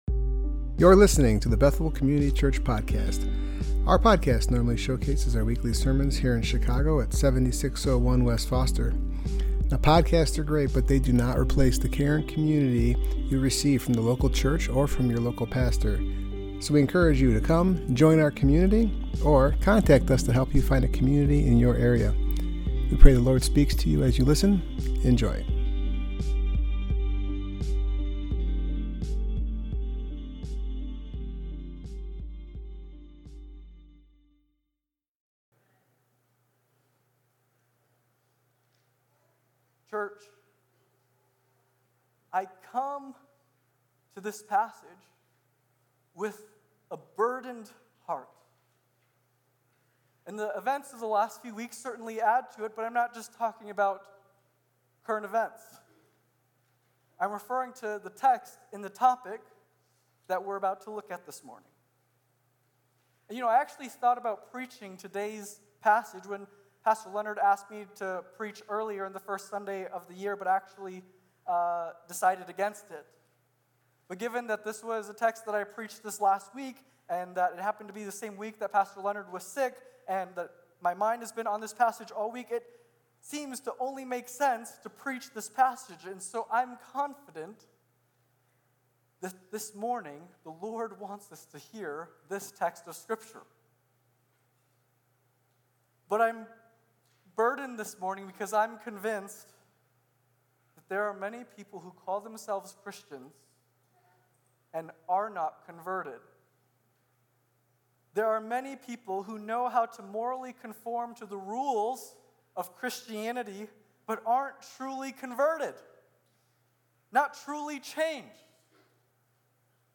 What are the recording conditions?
Passage: John 3:1-15 Service Type: Worship Gathering